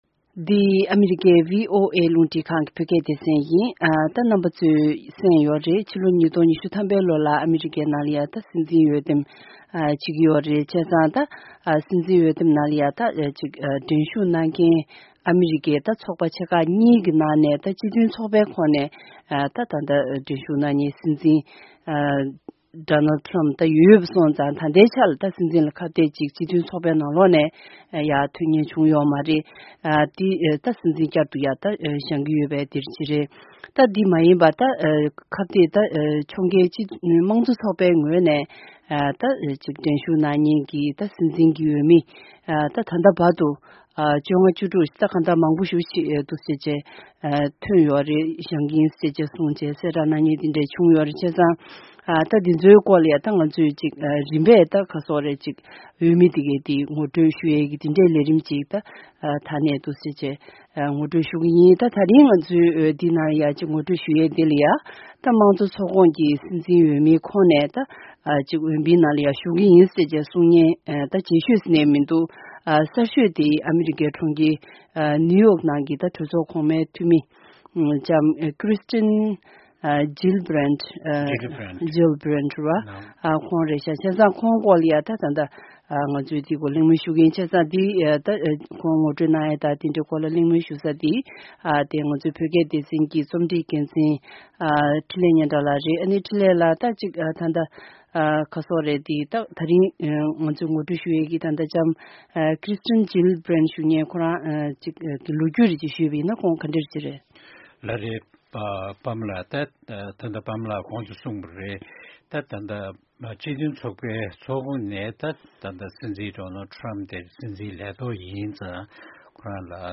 གཉིས་ཀྱིས་གླེང་མོལ་ཞུས་པ་ཞིག་གསན་རོགས་གནང་།།